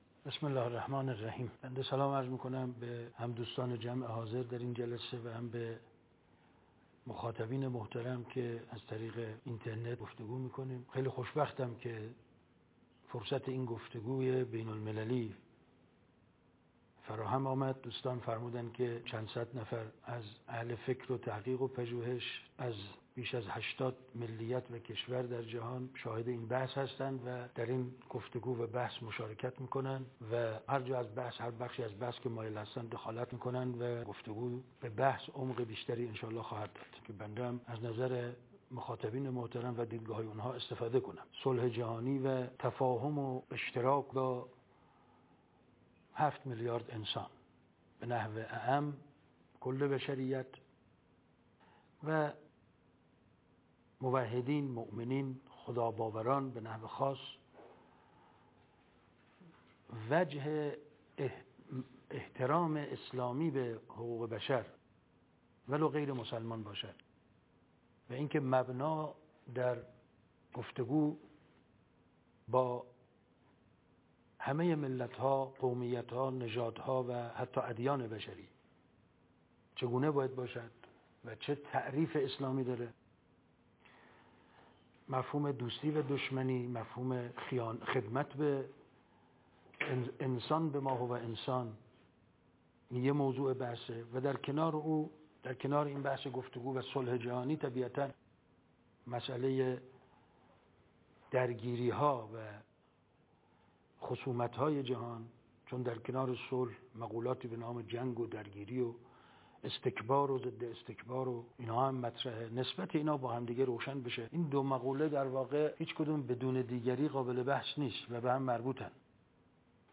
ویدئوکنفرانس با پژوهشگرانی در ۸۰ کشور جهان / صلح بین‌الملل و ضرورت جنگ علیه جنگ / ۱۳۹۷